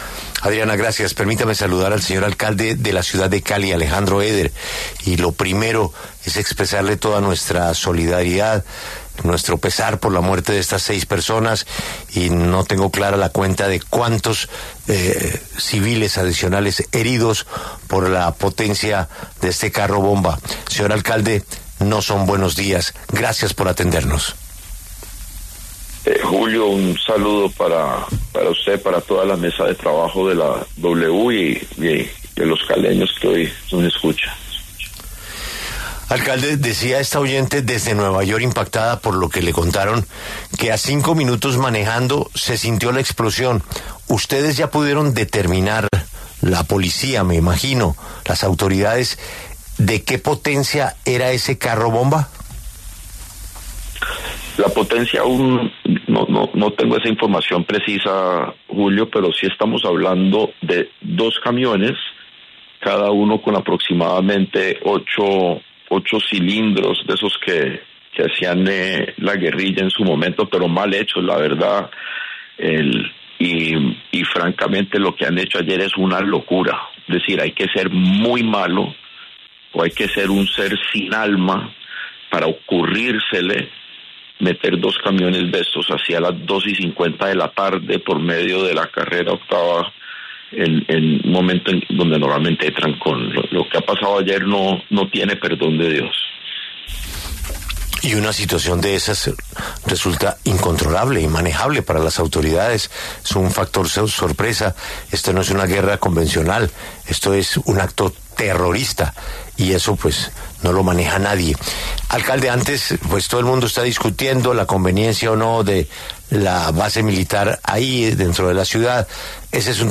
Alejandro Eder, alcalde de Cali, habló en los micrófonos de La W tras el atentado en cercanías a la Escuela Marco Fidel Suárez, que dejó 6 personas muertas y más de 70 heridas.